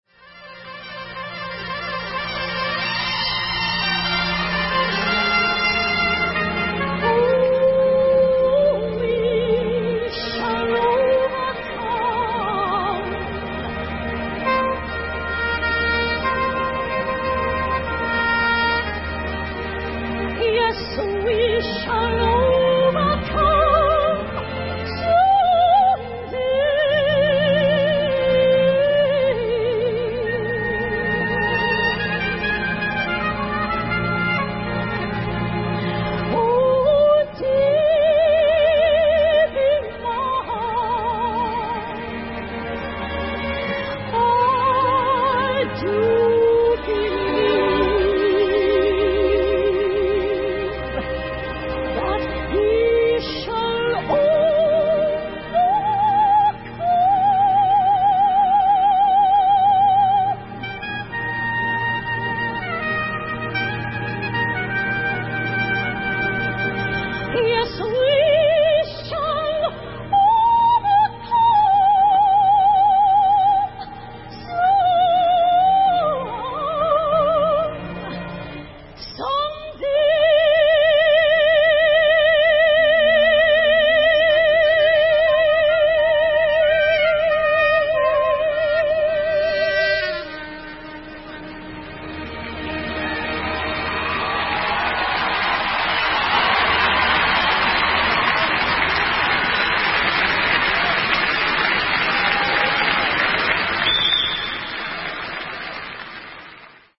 Да и пела-то Кэтлин Бэттл теперь уже вовсе не под гитару: певице аккомпанировал известный американский саксофонист Кеннет Горелик, или Кенни Джи (Kenny G).